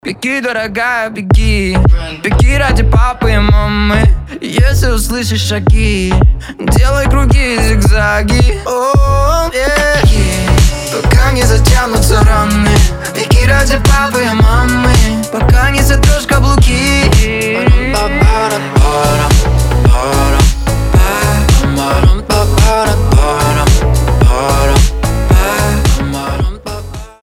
• Качество: 320, Stereo
мужской голос
ритмичные